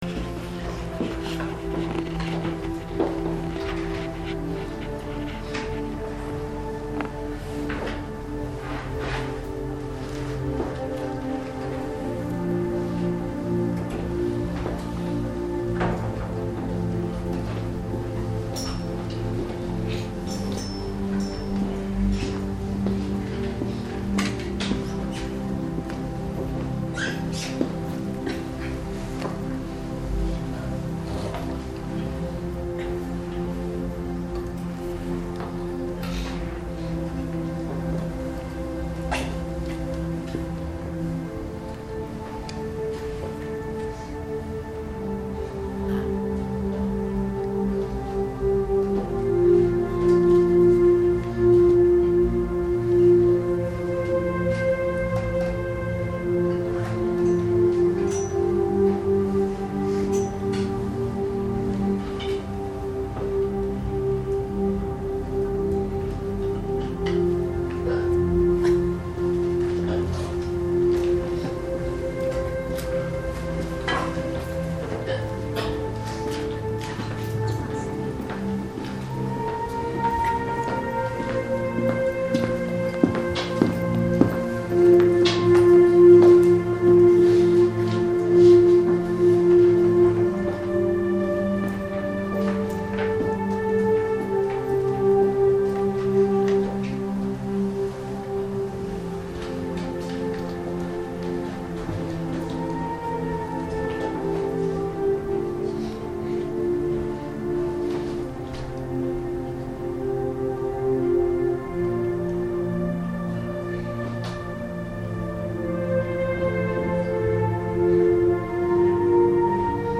正在播放：--主日恩膏聚会录音（2015-01-04）